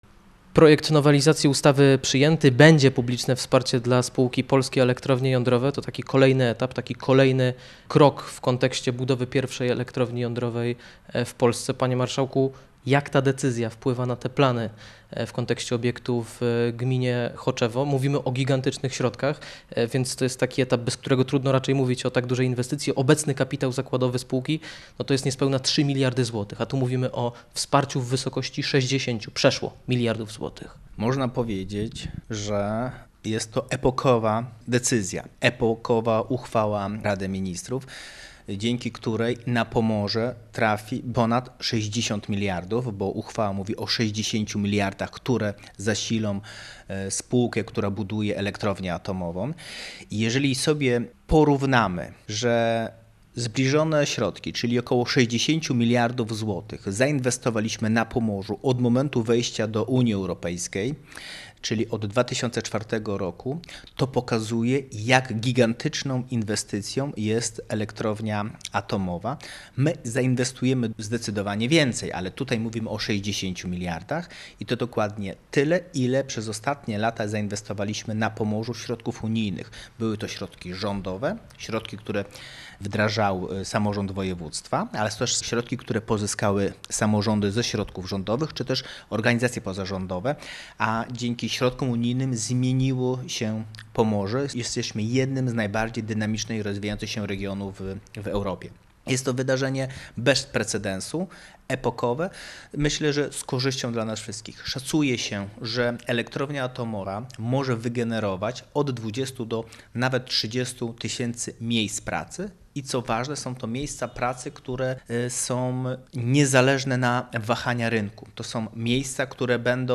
– To milowy krok – wskazał wicemarszałek województwa pomorskiego Marcin Skwierawski